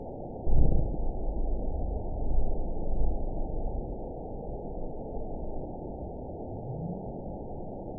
event 919953 date 01/30/24 time 01:23:43 GMT (1 year, 4 months ago) score 9.28 location TSS-AB09 detected by nrw target species NRW annotations +NRW Spectrogram: Frequency (kHz) vs. Time (s) audio not available .wav